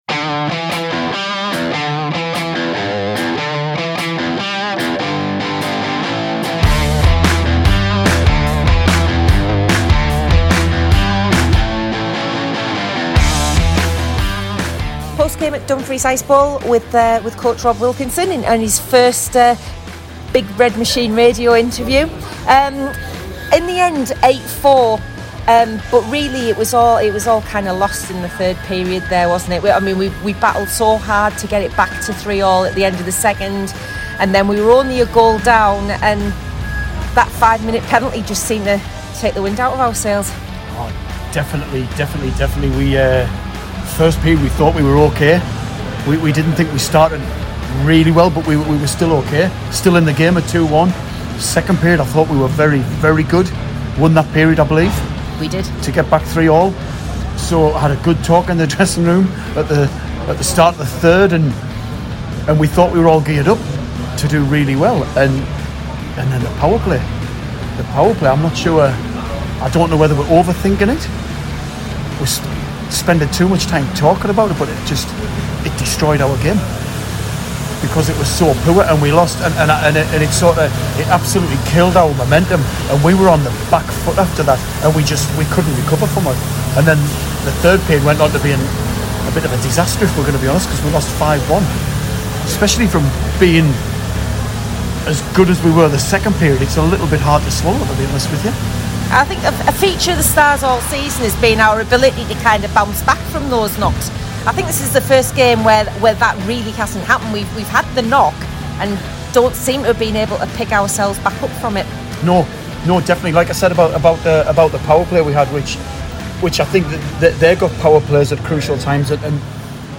post-game in Dumfries last weekend